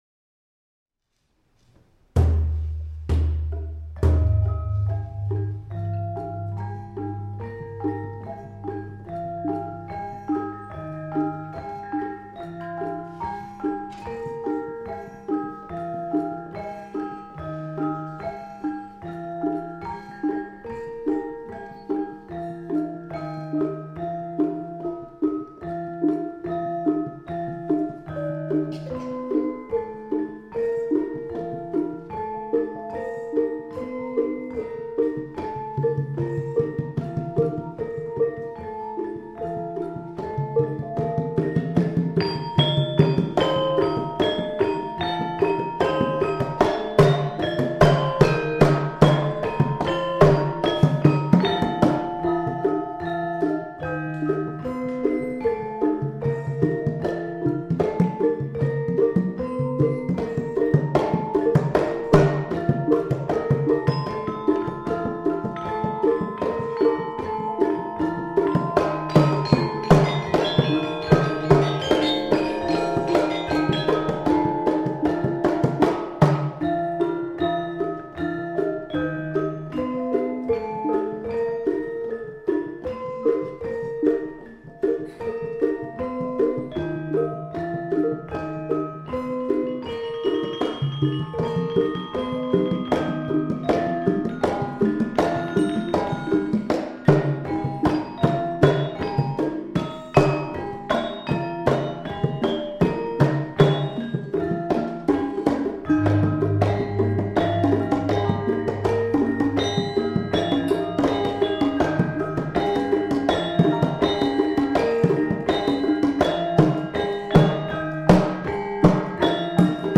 A Gamelan Composition Portfolio with Commentary: Collaborative and Solo Processes of Composition with Reference to Javanese Karawitan and Cultural Practice.